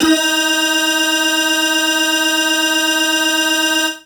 55bg-syn16-d#4.aif